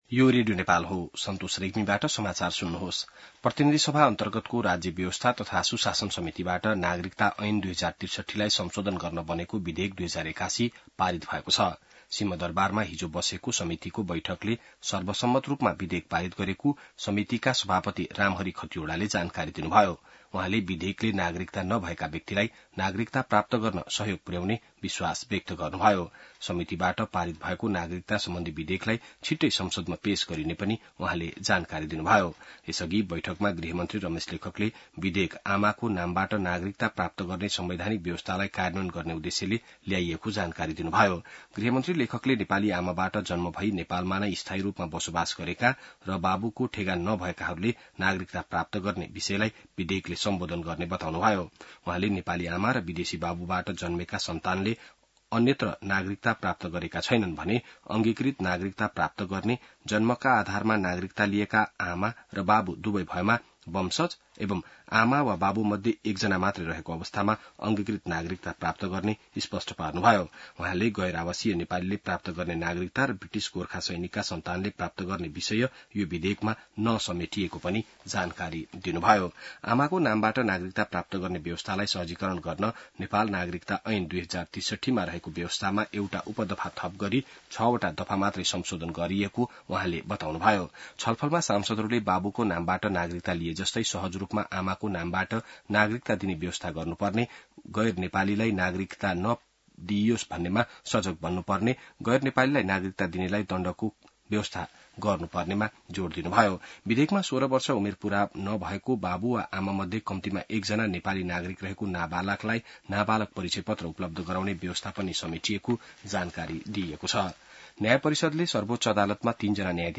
बिहान ६ बजेको नेपाली समाचार : २८ जेठ , २०८२